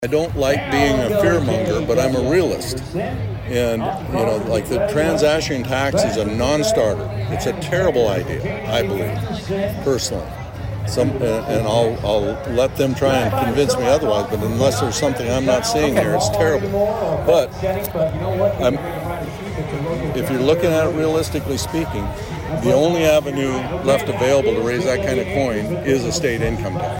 Prior to that Governor Rhoden did an interview with HubCityRadio to address a variety of topics.